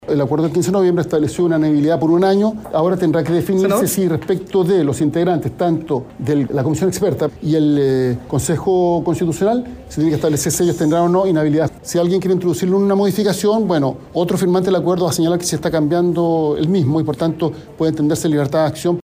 El presidente del Senado, Álvaro Elizalde, recordó que ya el proceso anterior fijó estos plazos en un año y además, describió por qué sería dañino hacer cambios al acuerdo.